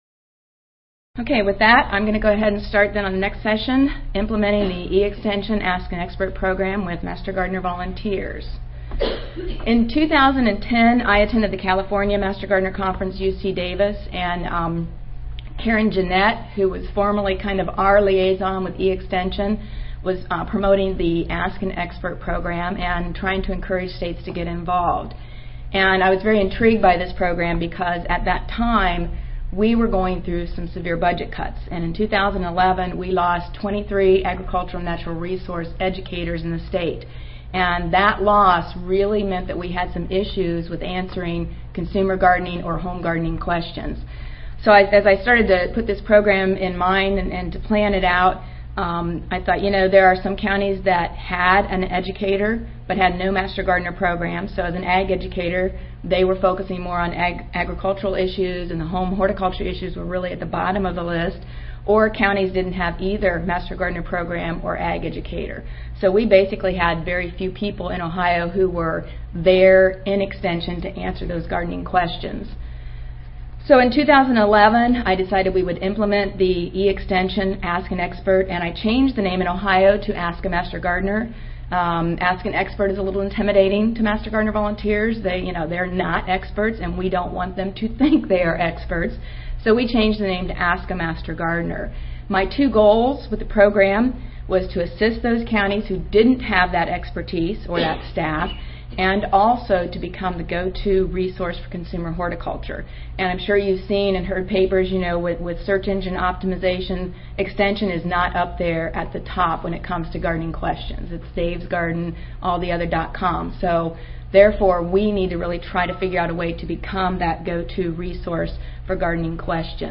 Recorded Presentation